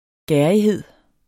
Udtale [ ˈgæɐ̯iˌheðˀ ]